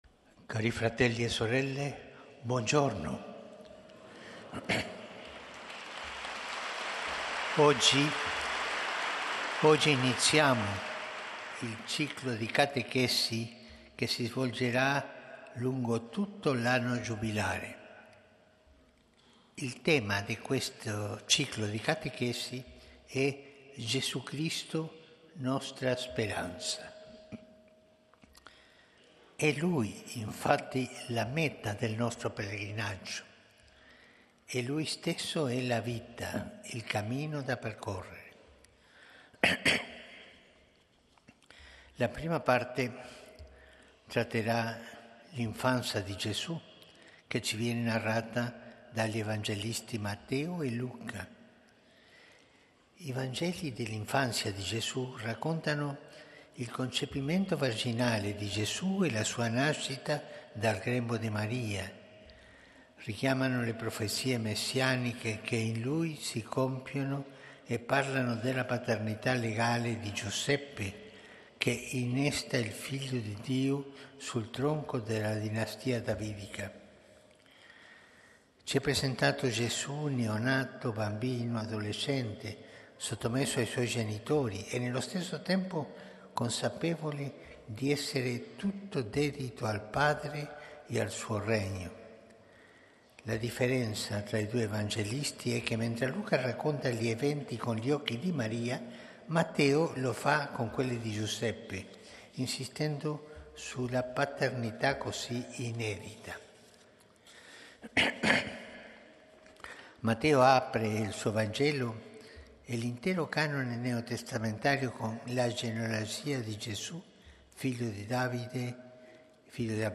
UDIENZA GENERALE di PAPA FRANCESCO
Aula Paolo VIMercoledì, 18 dicembre 2024